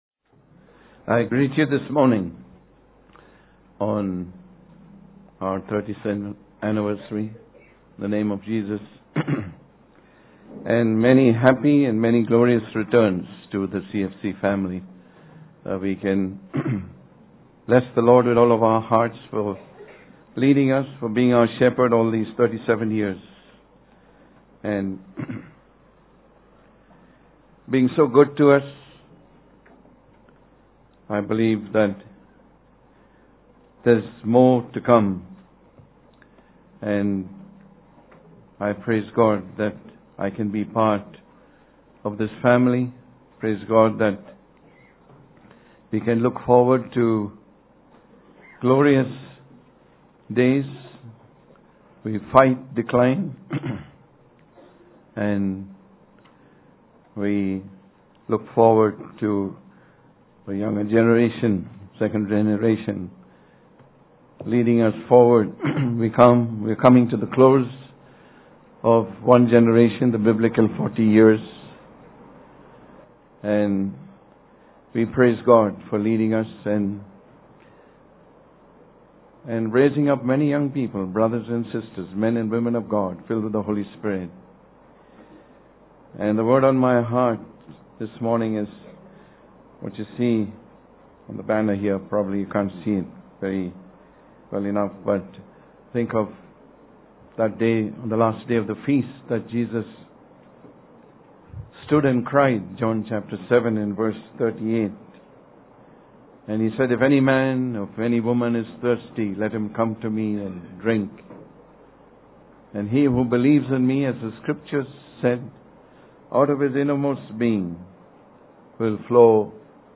37th CFC Anniversary Celebrations - Part 2